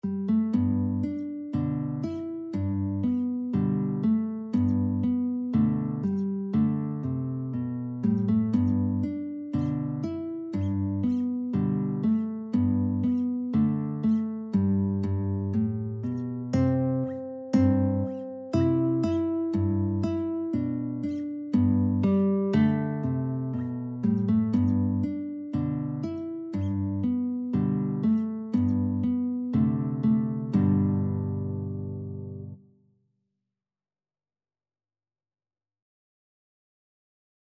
Guitar 1Guitar 2
A traditional song from the US.
Andante =120
4/4 (View more 4/4 Music)
Arrangement for Guitar Duet
G major (Sounding Pitch) (View more G major Music for Guitar Duet )